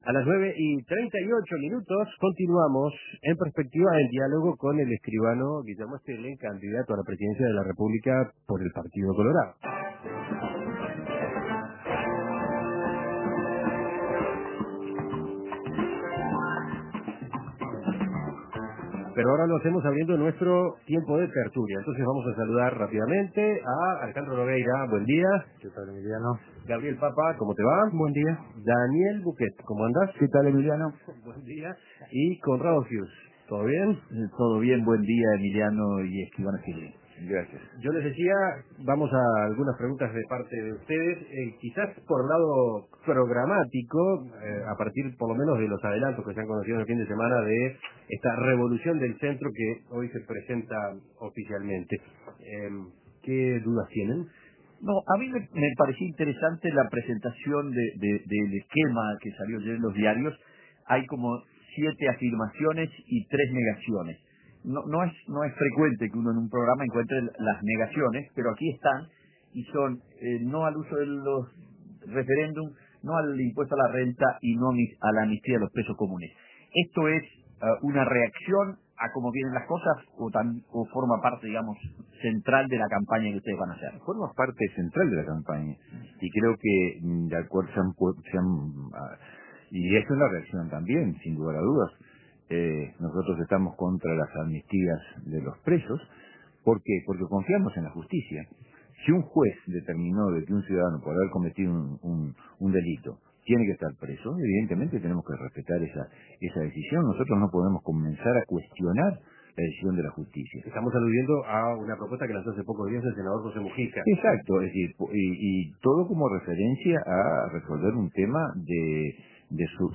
Los contertulios conversan con Guillermo Stirling, candidato a la Presidencia por el Partido Colorado